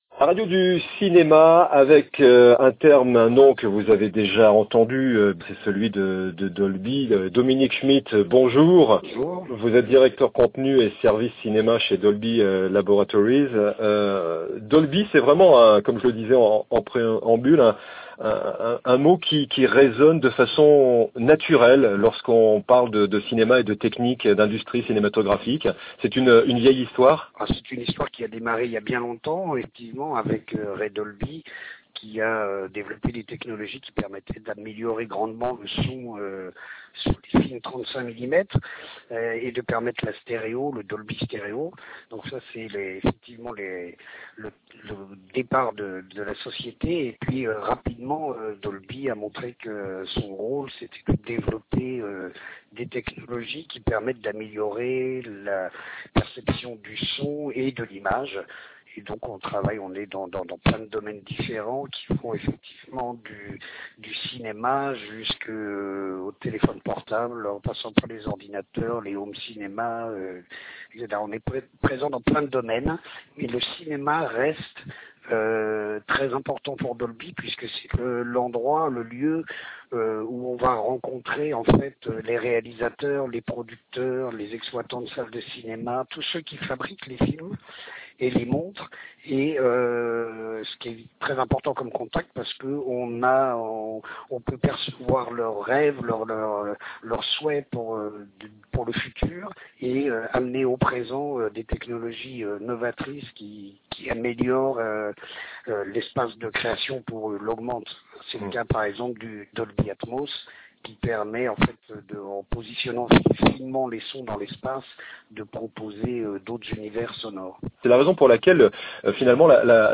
Podcasts cinéma : interviews | La Radio du Cinéma